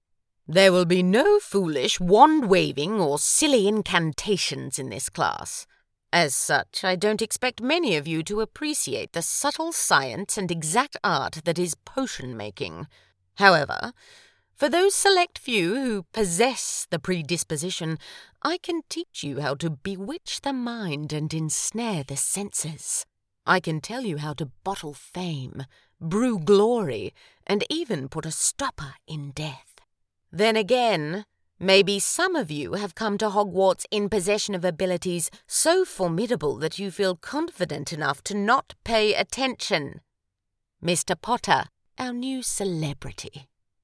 Natural, sincere and friendly. From authoritative explainer narration to bright and warm commercial tones.